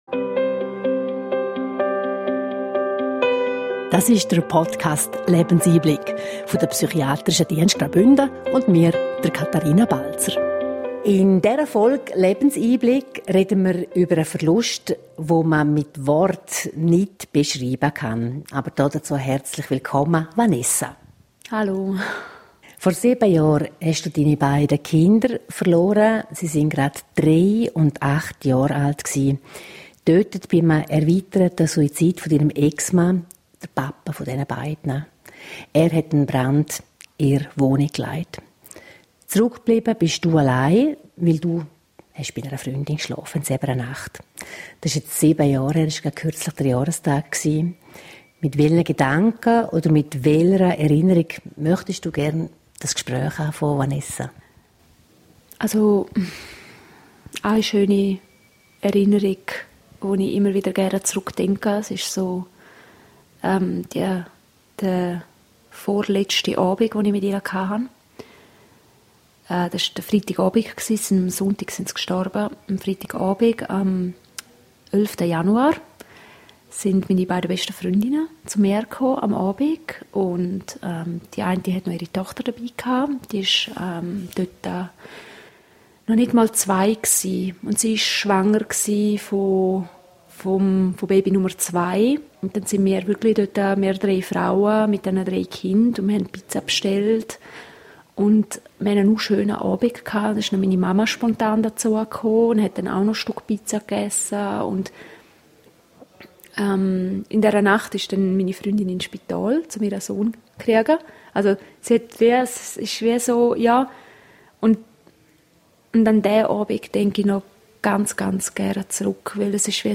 Ein berührendes Gespräch über Schmerz, Spiritualität, Selbstheilung und die Hoffnung, dass selbst nach der dunkelsten Zeit wieder Licht möglich ist und Mut für alle, die selbst schwere Schicksalsschläge erleben mussten.